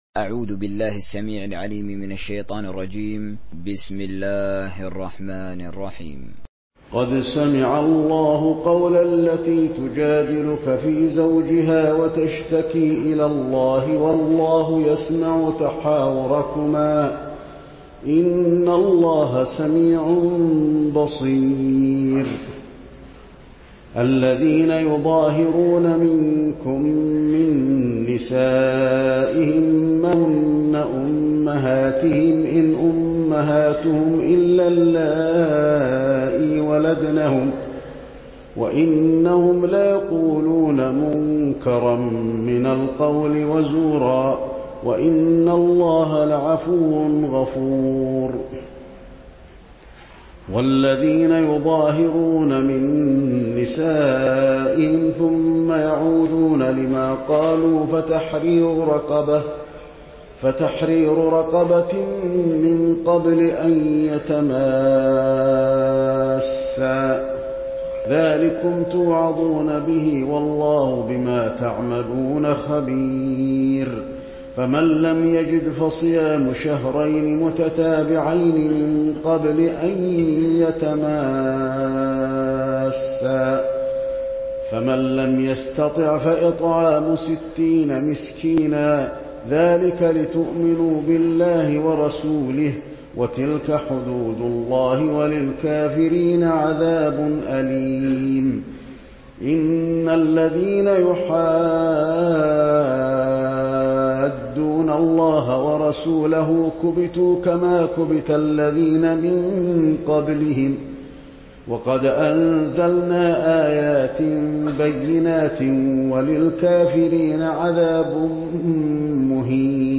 تراويح (رواية حفص)